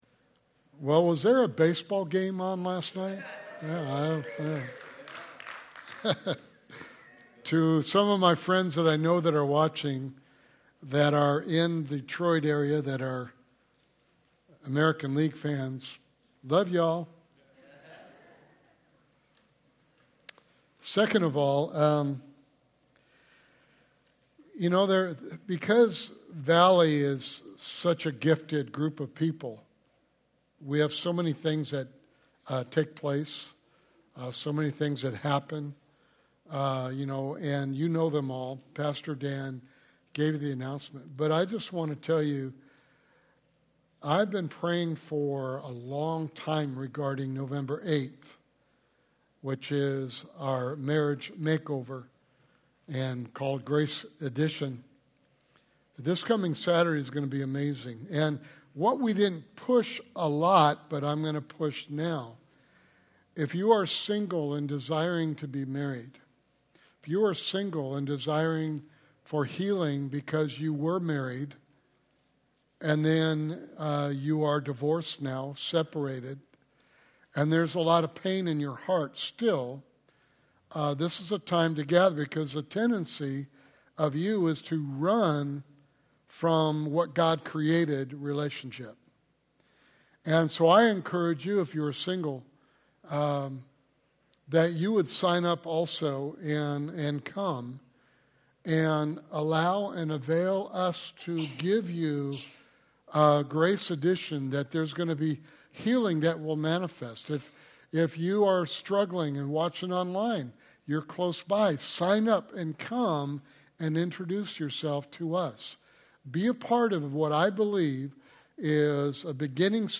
Sermon Series: The God I Never Knew